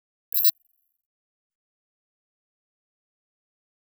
Futurisitc UI Sound 21.wav